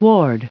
1448_ward.ogg